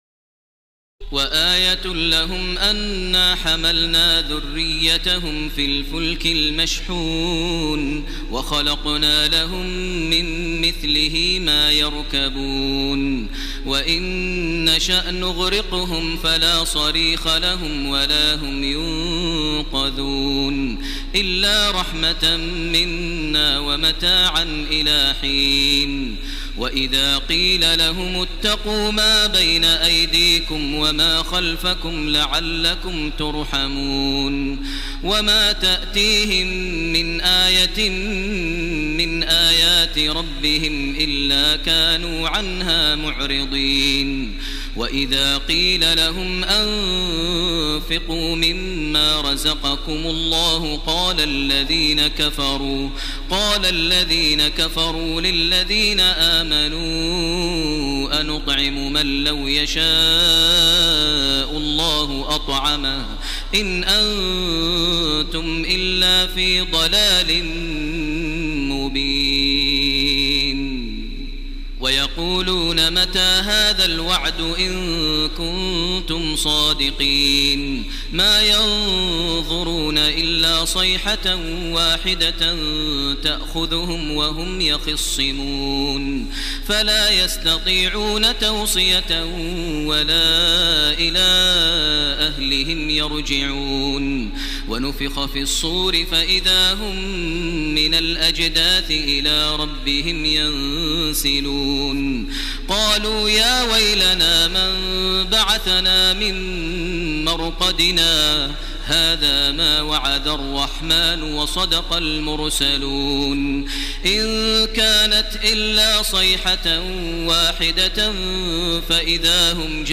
تراويح ليلة 22 رمضان 1430هـ من سور يس (41-83) و الصافات كاملة Taraweeh 22 st night Ramadan 1430H from Surah Yaseen to As-Saaffaat > تراويح الحرم المكي عام 1430 🕋 > التراويح - تلاوات الحرمين